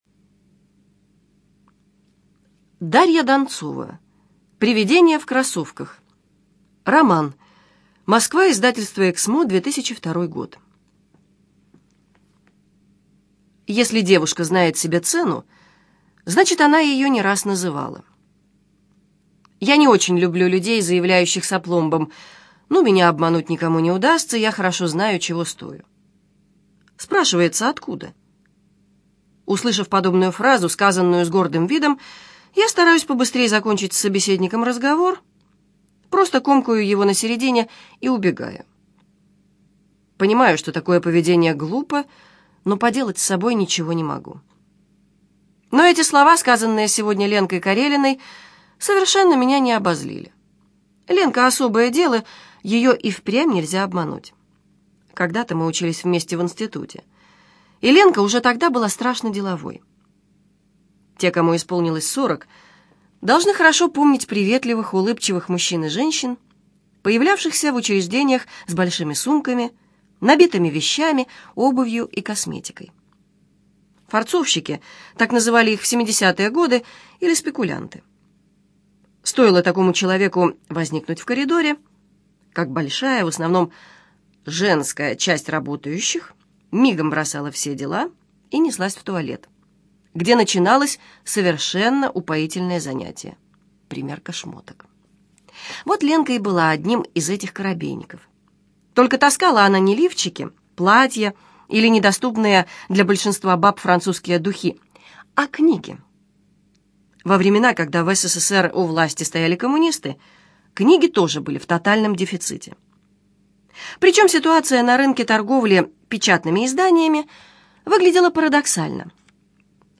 Аудиокнига Привидение в кроссовках - купить, скачать и слушать онлайн | КнигоПоиск